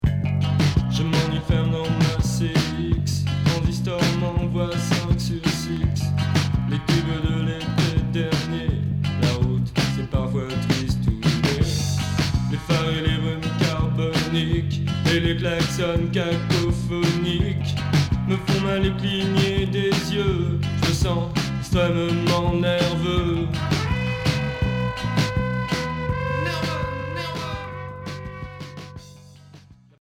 Rock new wave